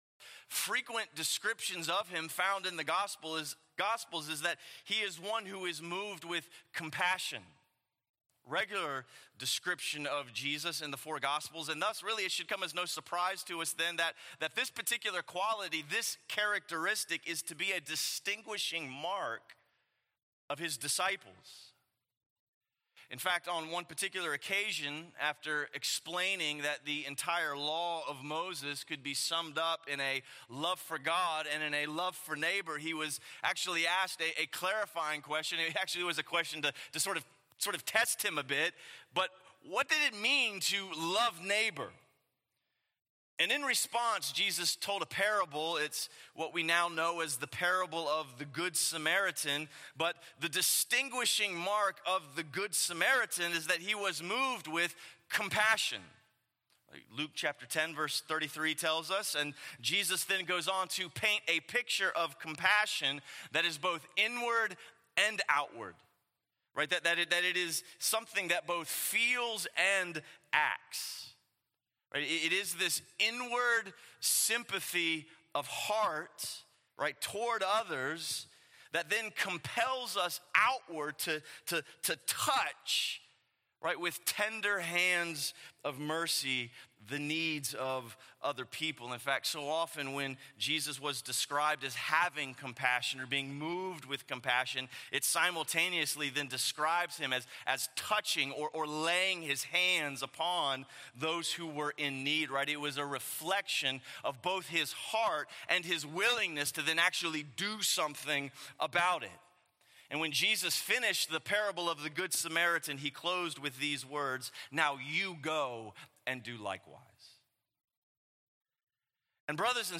Feeding the 5,000 – Stand Alone Sermons | Crossway Community Church